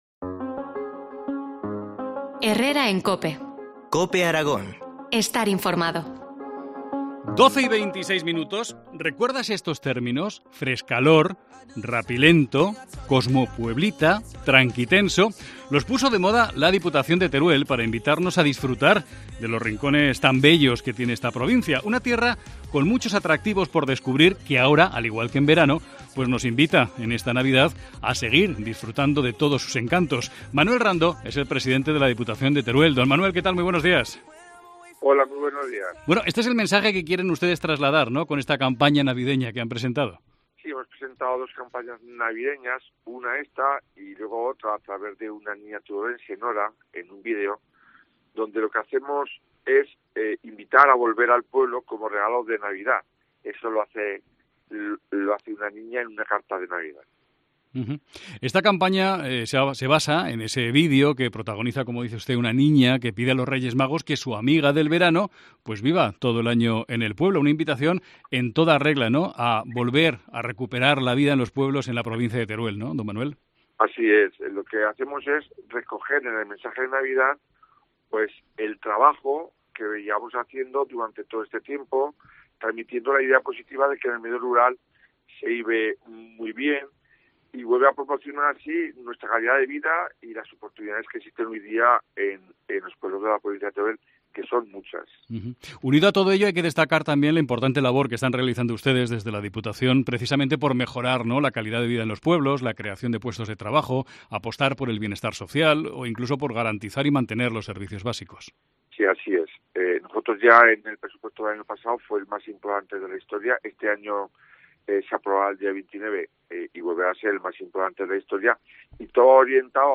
Entrevista a Manuel Rando, presidente de la Diputación Provincial de Teruel.